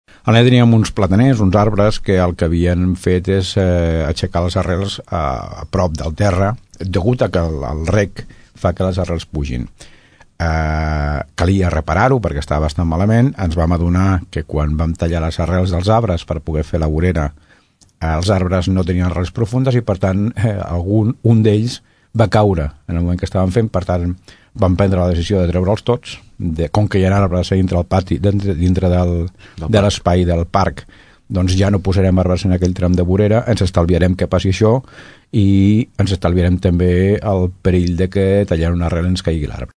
Per aquest motiu, les obres han servit per treure aquests arbres, refer la tanca de fusta i instal·lar una nova vorera, que s’ha eixamplat fins a la mateixa alçada que l’escola. Ho explica l’alcalde de Tordera, Joan Carles Garcia.